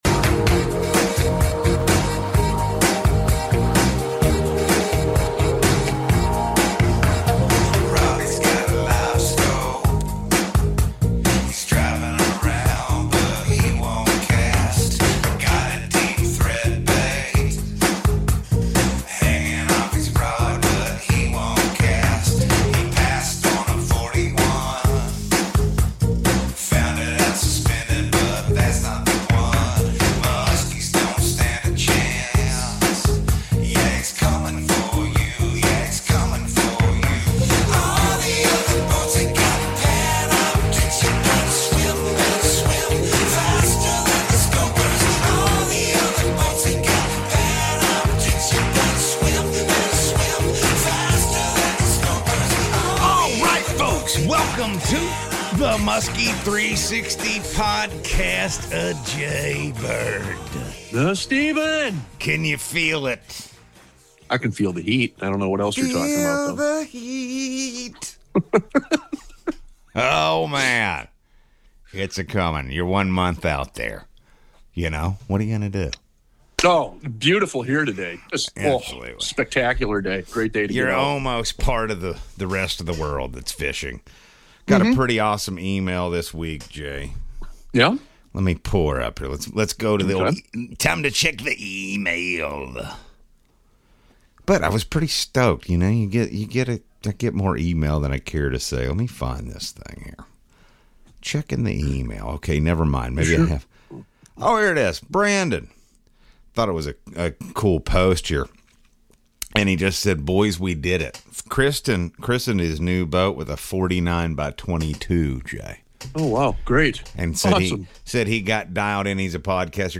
Musky Fishing tips and tactics and conversations with the best musky fishermen in the world.